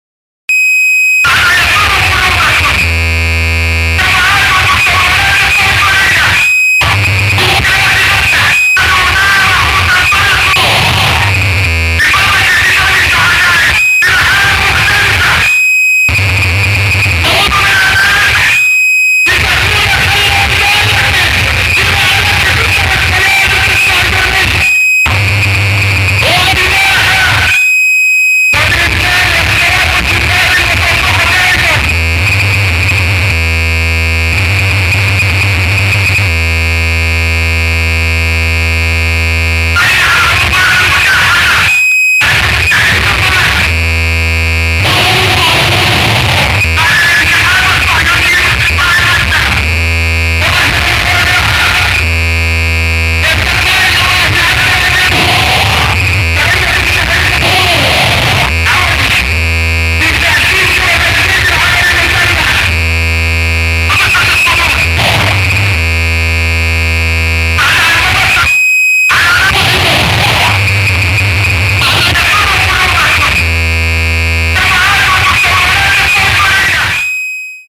Harsh Power-Electronics
• Genre: Power Electronics